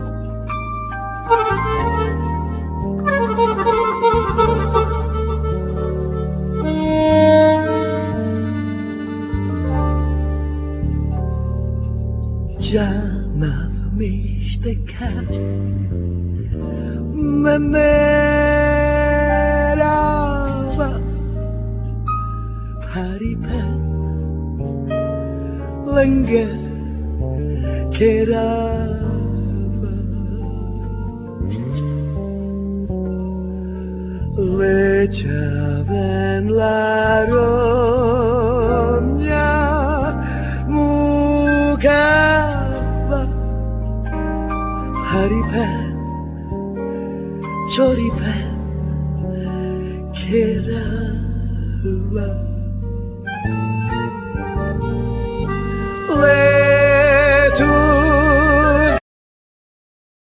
Vocal,Cello
Guitars,Vocal
Piano,Fender piano model 88,Vocal
Accordion
Violin
Bass-guitar,Double bass
Drums